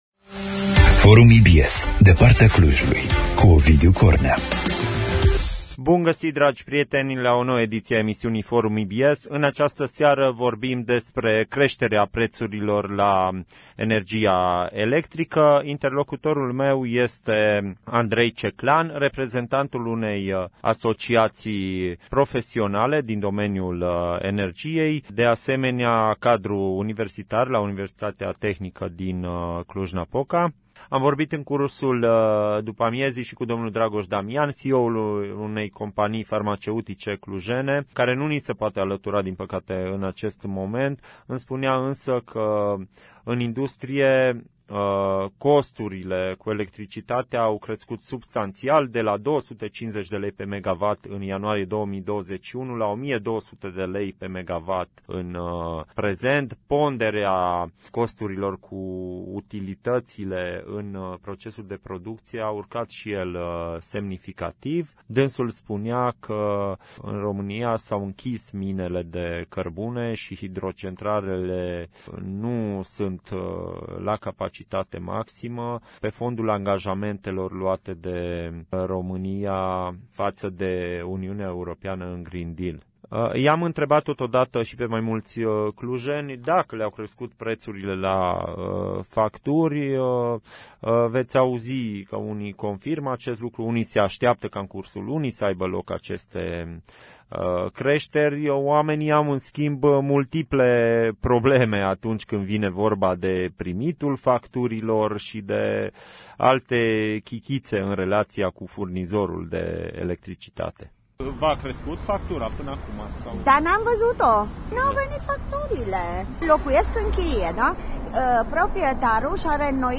interviul integral